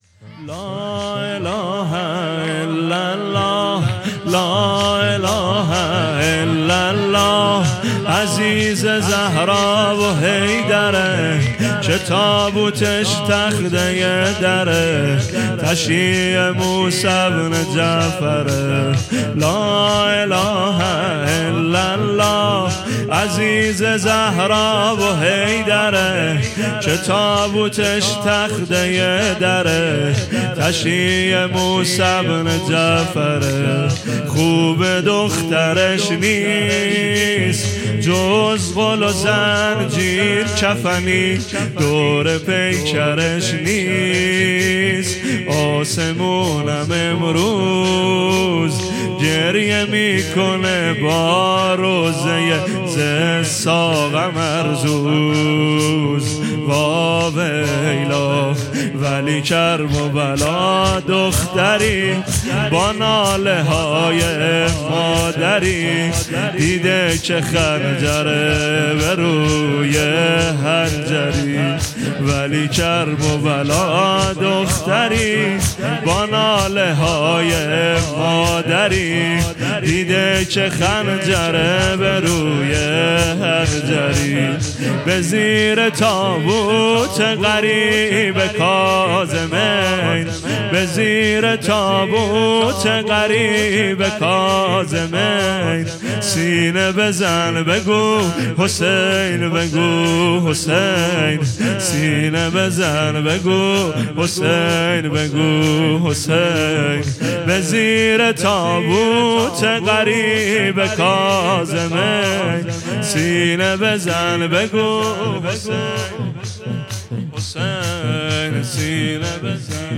شب شهادت امام کاظم علیه السلام